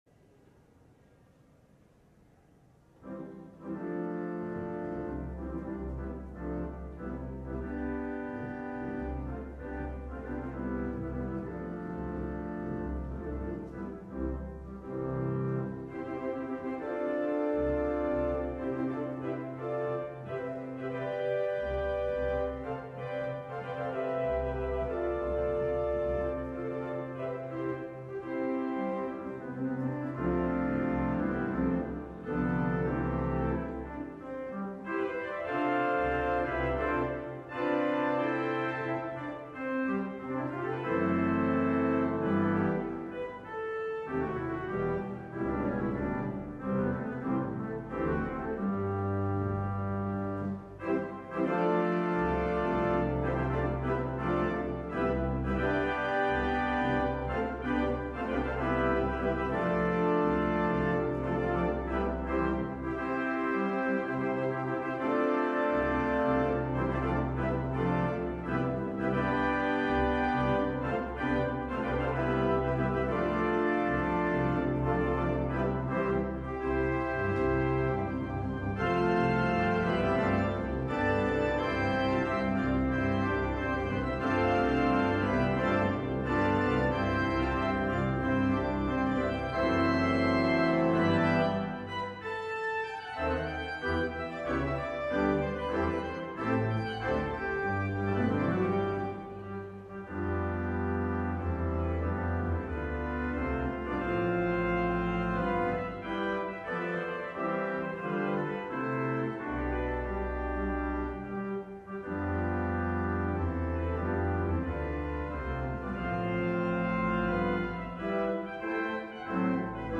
LIVE Evening Worship Service - Ready or Not
Congregational singing—of both traditional hymns and newer ones—is typically supported by our pipe organ.